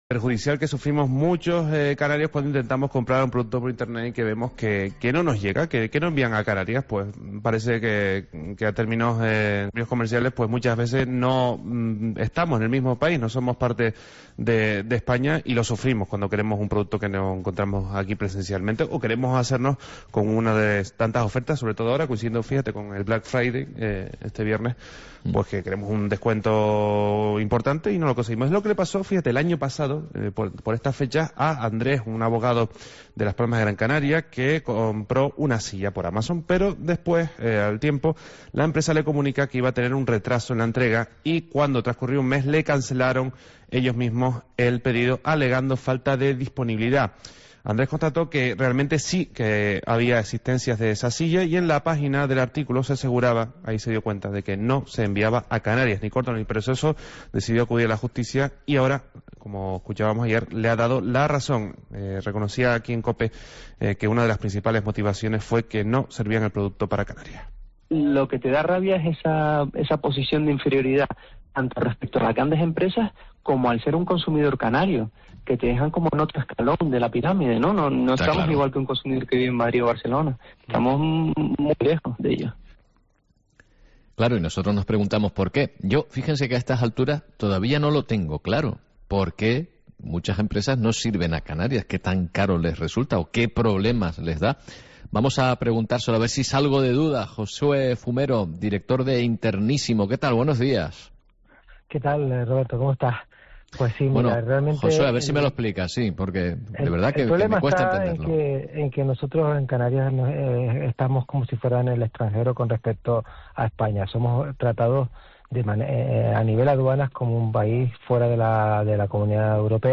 Esta semana, en el programa La mañana de COPE Canarias, hablamos sobre esta situación que no beneficia en absoluto a los canarios.